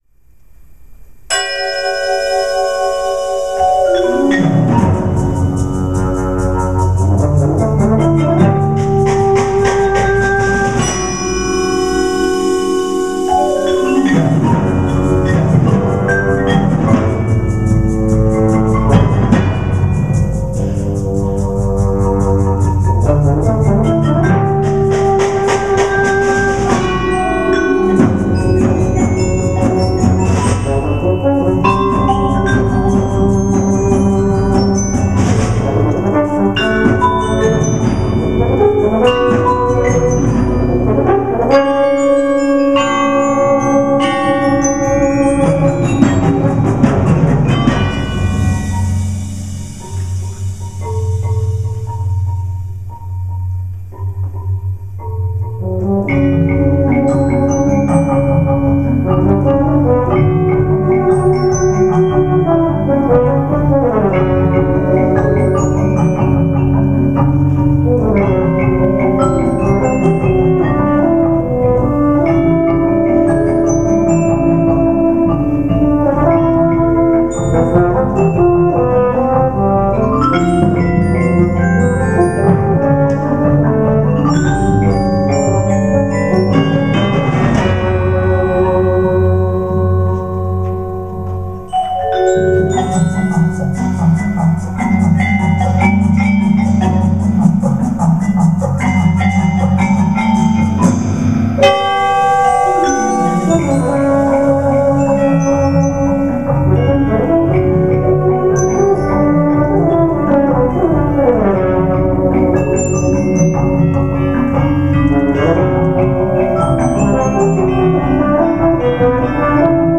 Genre: Solo Euphonium with Percussion Ensemble
Solo Euphonium
Bells/Crotales (2 octaves)
Chimes
Vibraphone
Marimba 1 (4-octave)
Marimba 2 (5-octave)
Percussion 1 (4 timpani, sizzle cymbal, triangle, mark tree)
Percussion 3 (kick bass drum, concert bass drum, tam-tam)